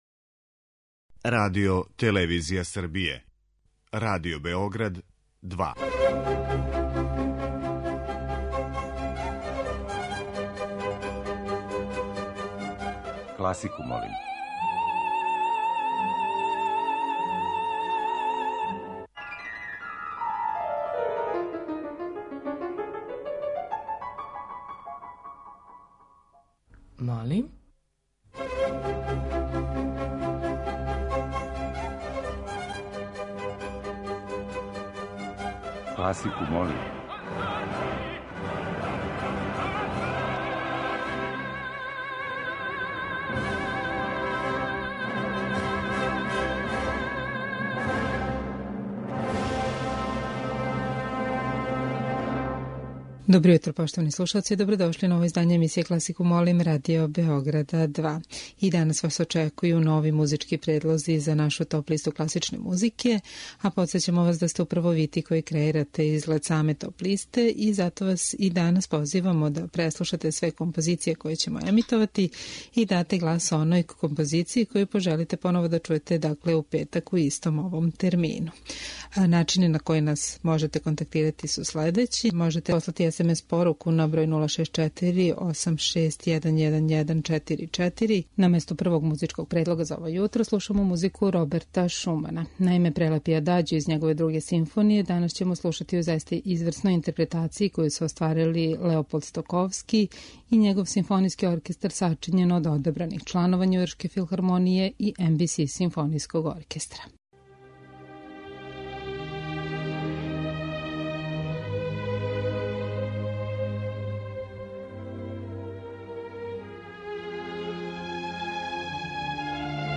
Избор за недељну топ-листу класичне музике РБ2